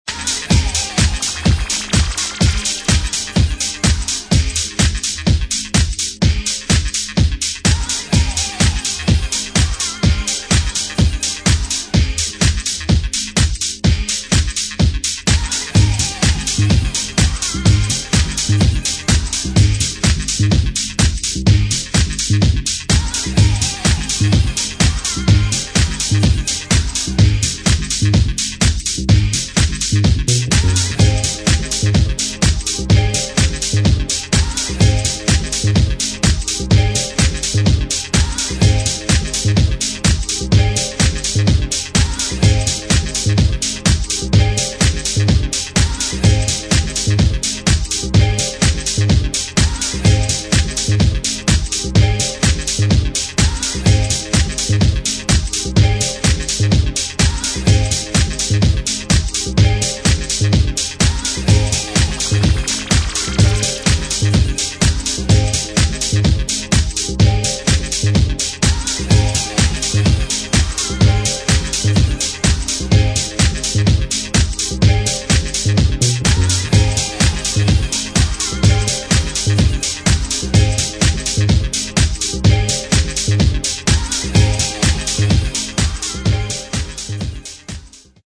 [ DEEP HOUSE / NY HOUSE ]